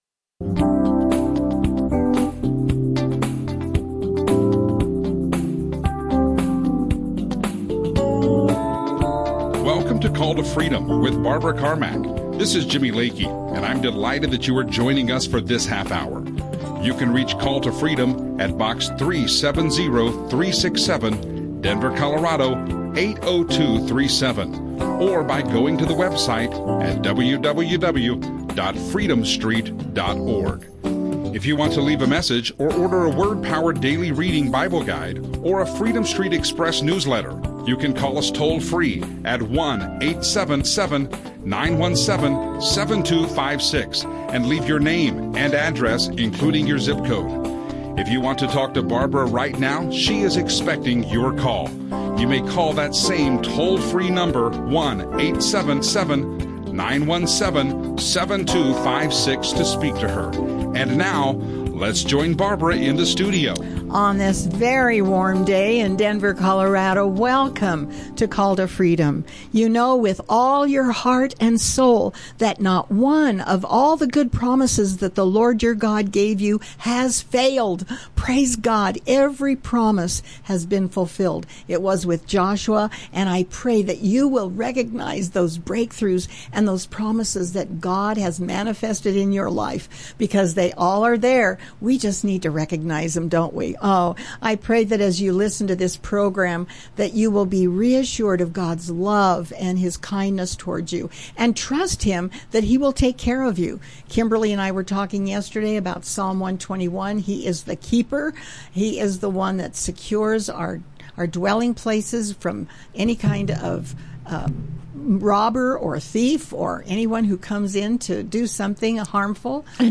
Christian talk
live radio show